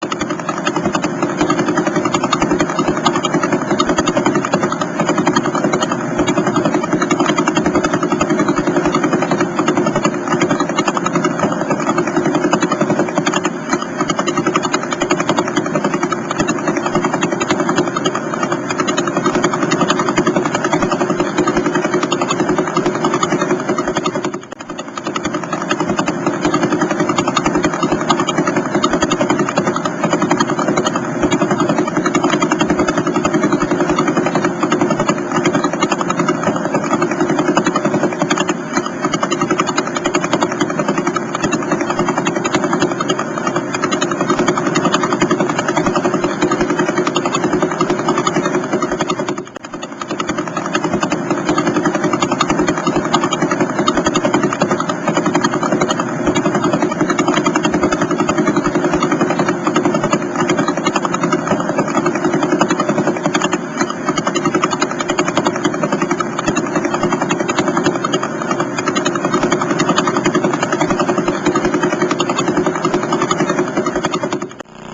Звуки звёзд, пульсаров
Эти уникальные аудиодорожки, преобразованные из электромагнитных колебаний, идеально подходят для создания атмосферной музыки, монтажа видеороликов, научной работы или глубокой релаксации.
Звук пульсара Вела делает одиннадцать оборотов в секунду